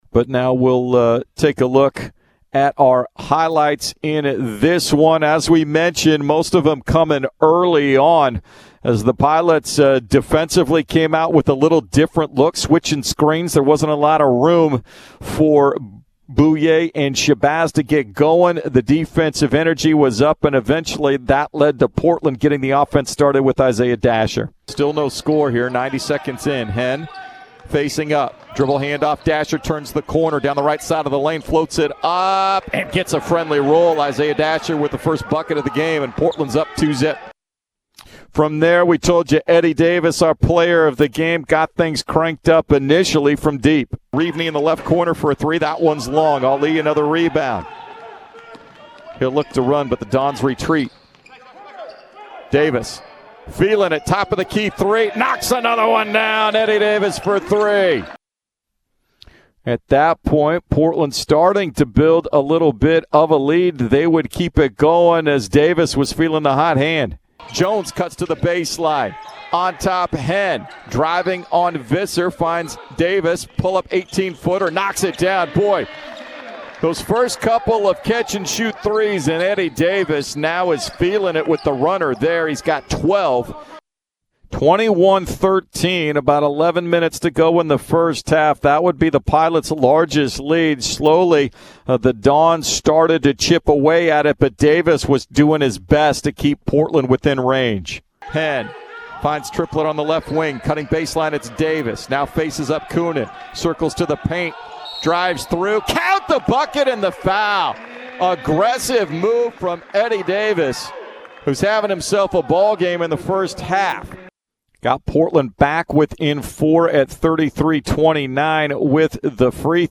Men's Basketball Radio Highlights vs. San Francisco
January 14, 2021 Radio highlights from Portland's 79-63 loss to the San Francisco Dons on Jan. 14, 2021 at teh Chiles Center.